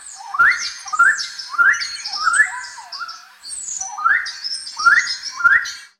Tropical Birds
A chorus of colorful tropical birds calling and singing in a lush rainforest canopy
tropical-birds.mp3